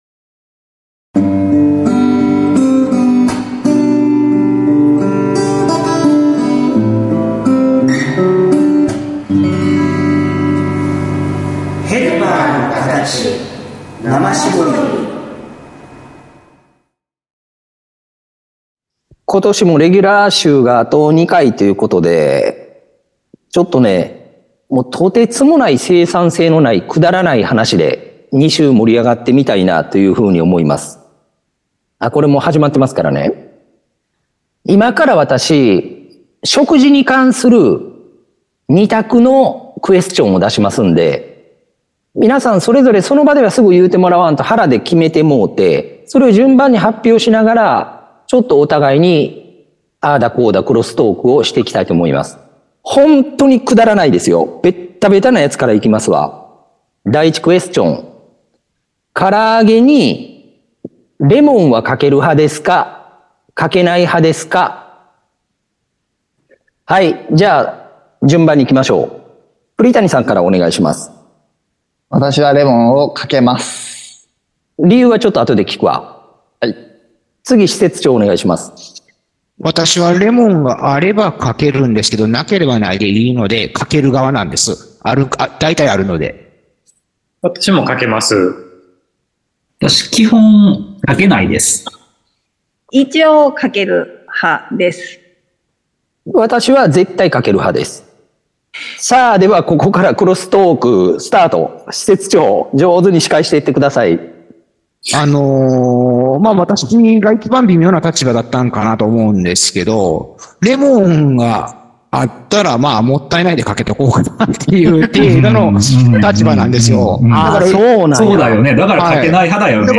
＜今週のテーマ＞ 食事に関する２択質問を テーマに展開するフリー トークの配信です。実に ま〜ったりしたペースで 進行する駄話をお楽しみ 下さい！